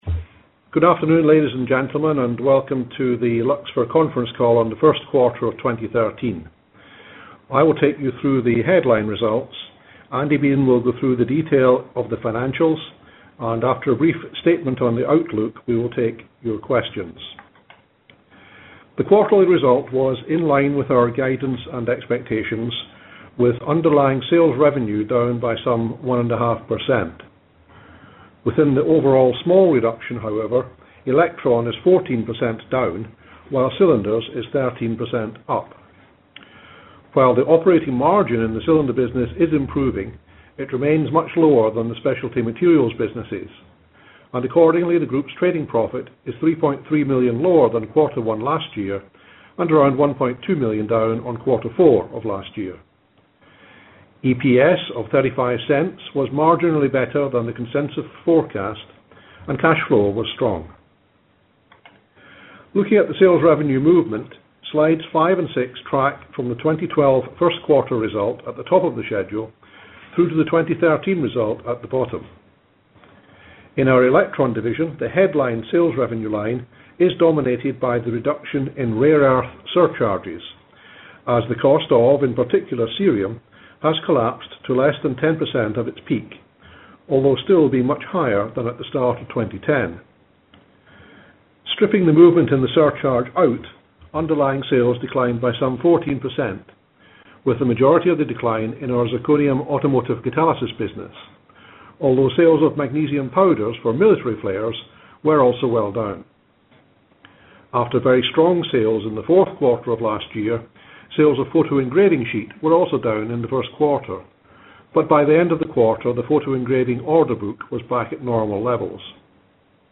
Conference Call Audio